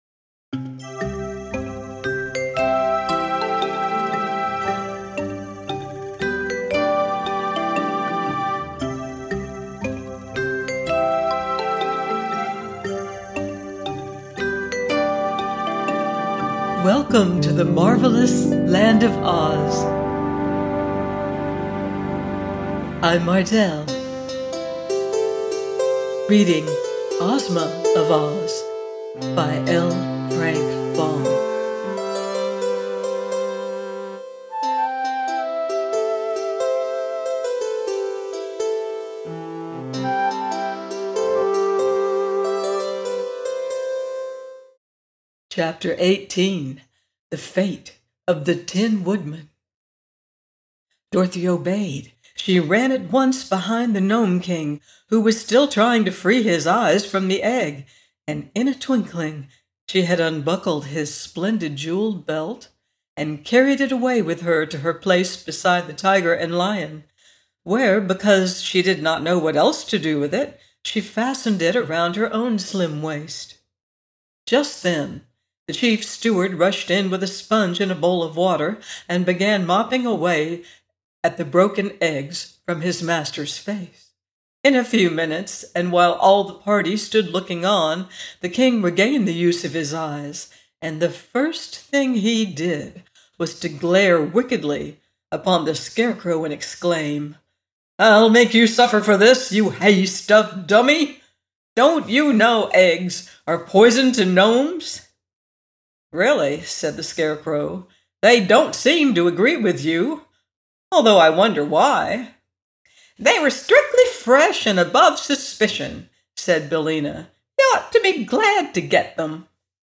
Ozma Of Oz – by L. Frank Baum - audiobook